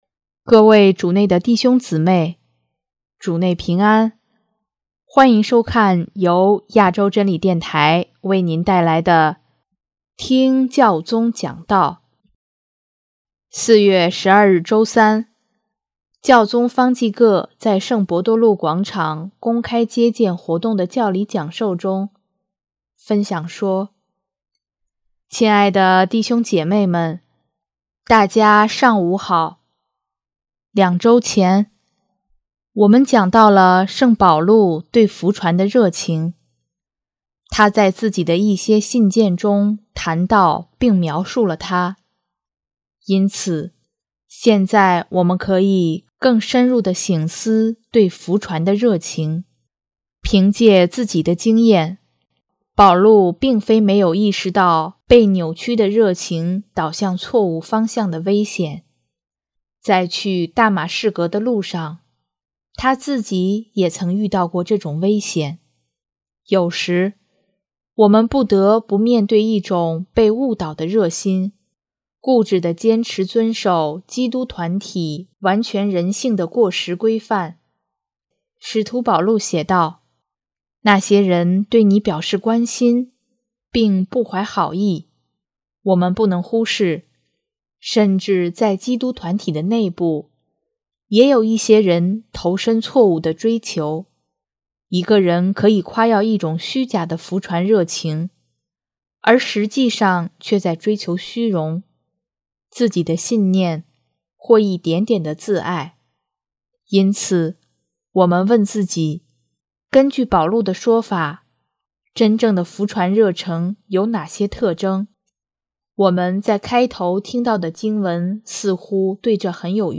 4月12日周三，教宗方济各在圣伯多禄广场公开接见活动的教理讲授中，分享说：